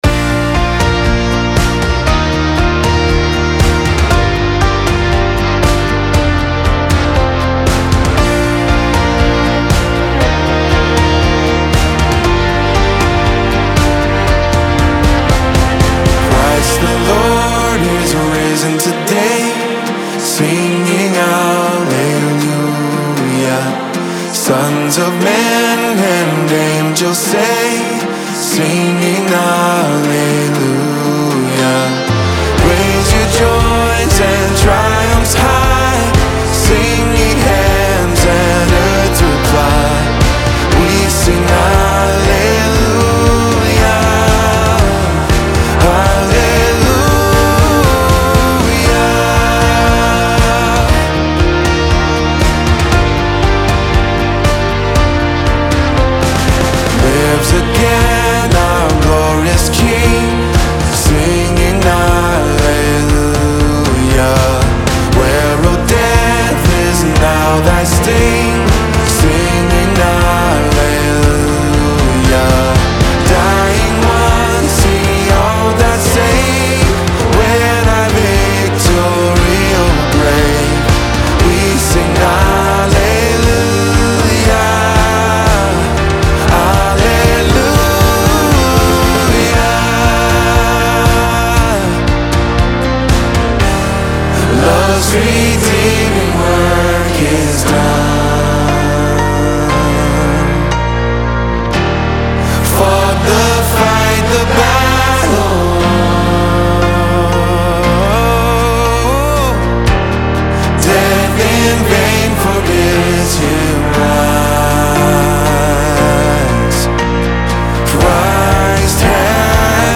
WeSingAlleluia(ChristTheLord)_VocalDemo.mp3